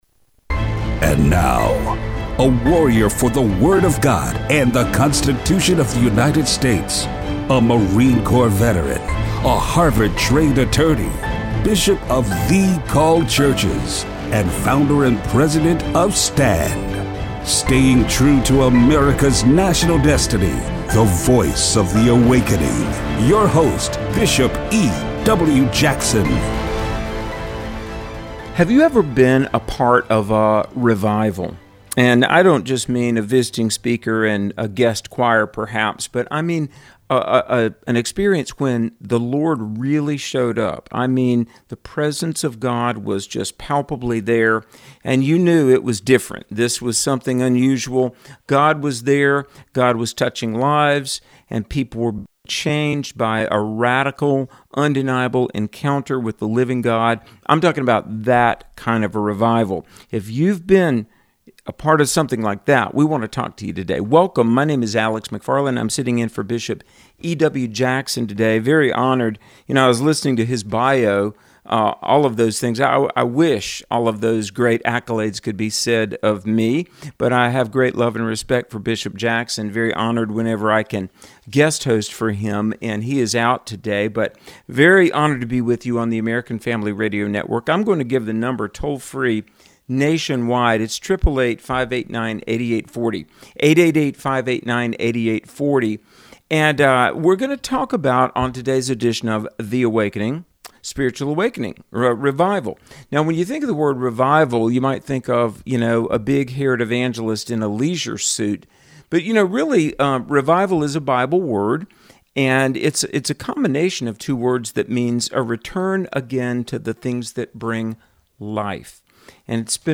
Listener call.